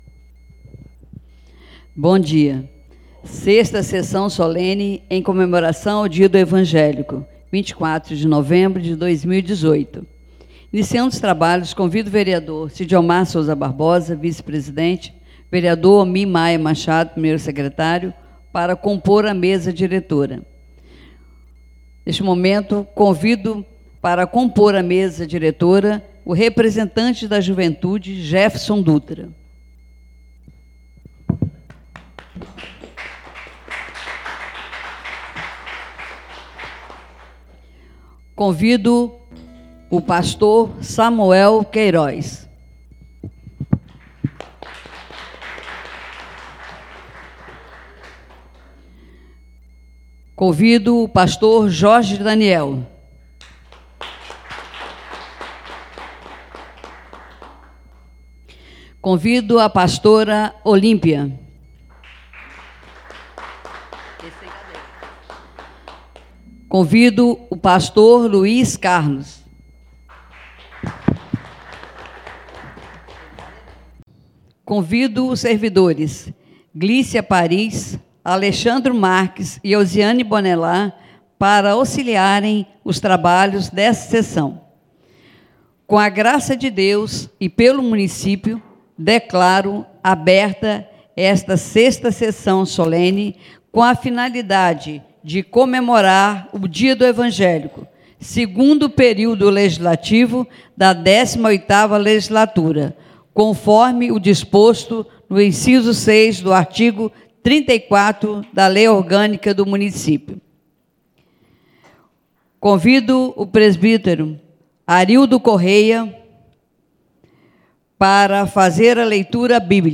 6ª SESSÃO SOLENE DIA DO EVANGÉLICO